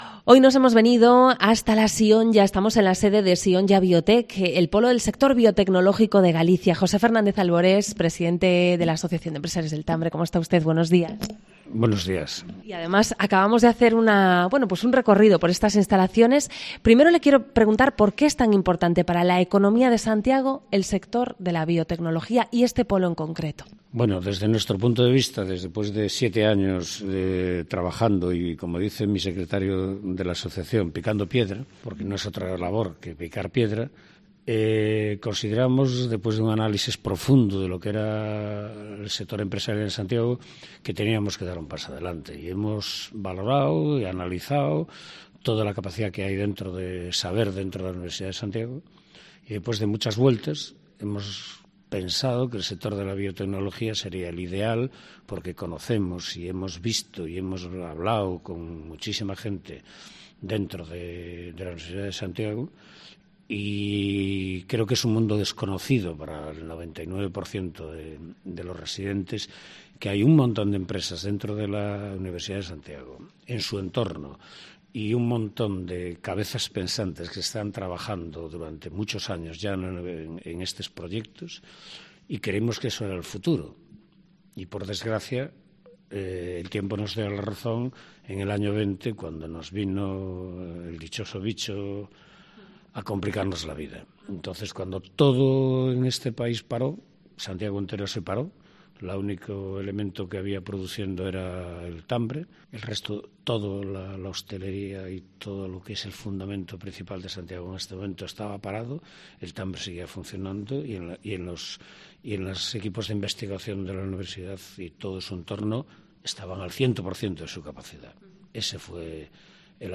Los empresarios del Tambre y una de las empresas de biotecnología que se asentarán en el polo atienden las preguntas de COPE Santiago